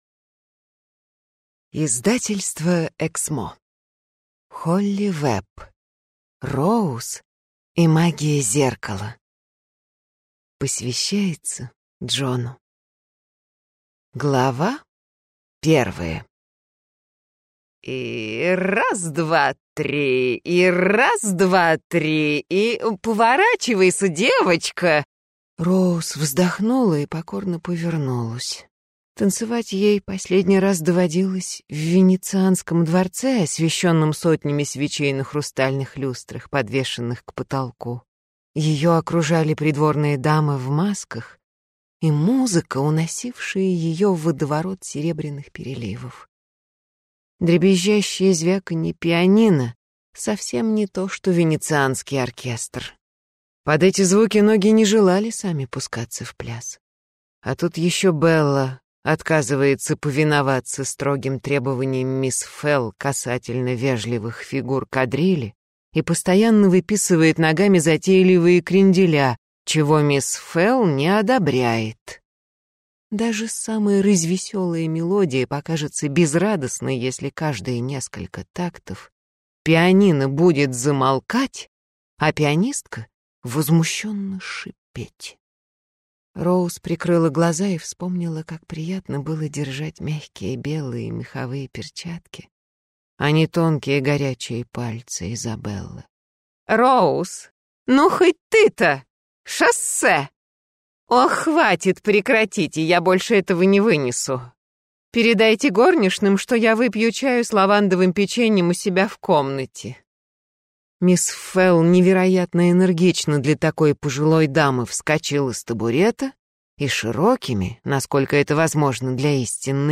Аудиокнига Роуз и магия зеркала | Библиотека аудиокниг
Прослушать и бесплатно скачать фрагмент аудиокниги